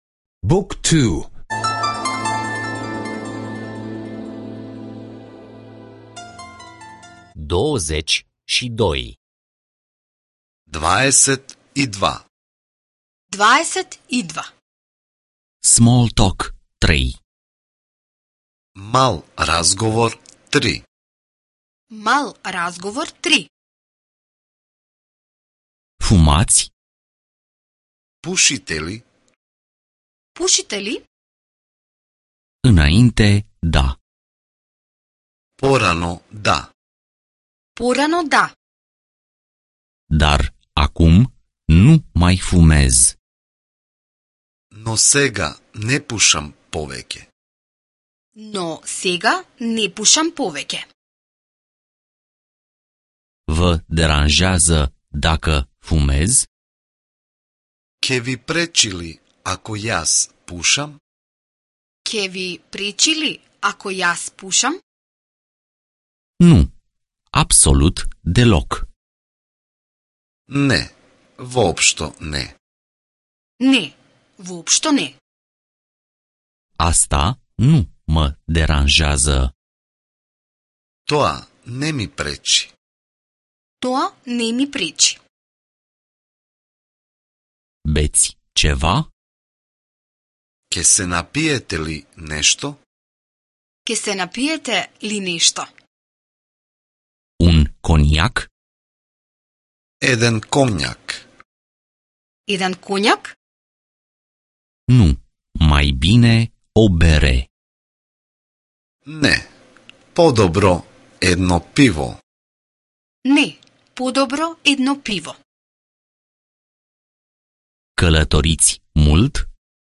Lecții audio de limba macedonenă